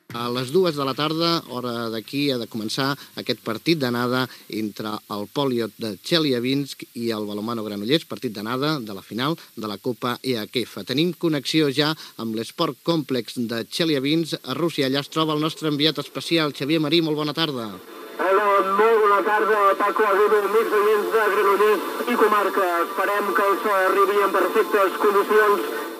Connexió amb Rússia per fer la transmissió del partit d'anada de la Copa EHF d'Handbol masculí entre el Poliot Txeliàbinsk i BM Granollers
Esportiu